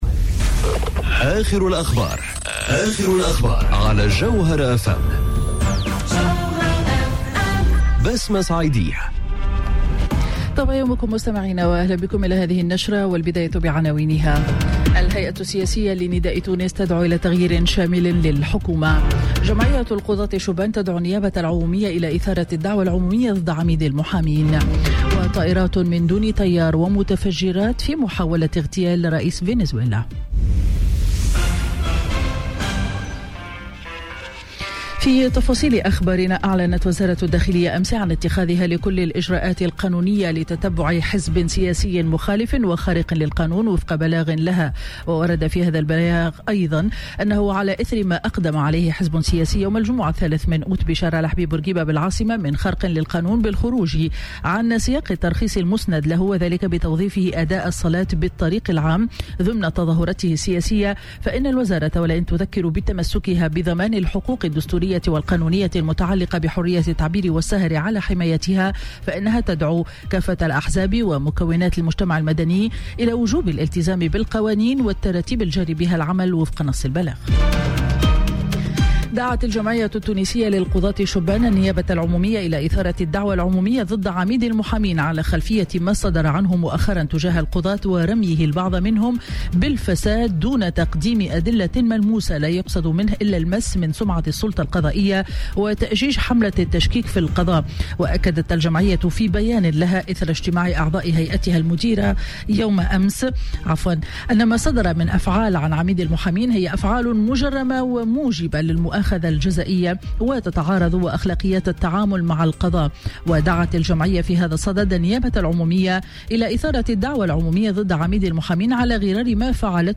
نشرة أخبار السابعة صباحا ليوم الأحد 5 أوت 2018